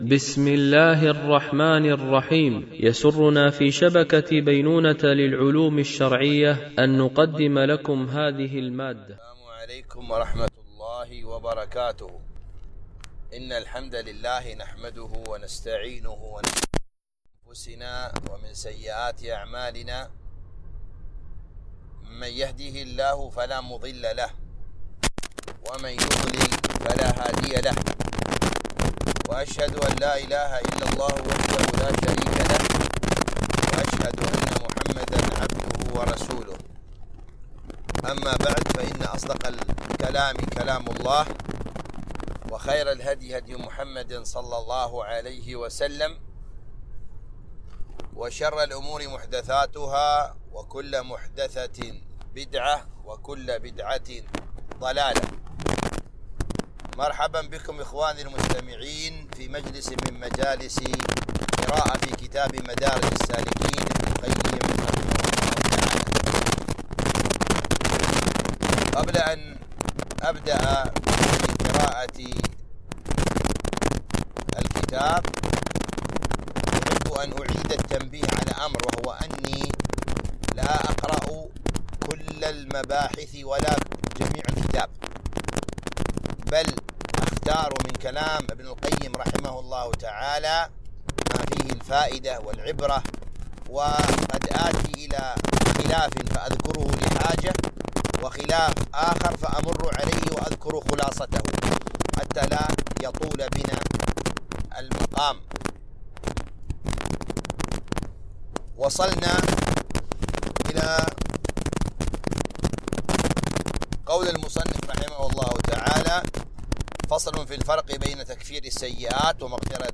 قراءة من كتاب مدارج السالكين - الدرس 35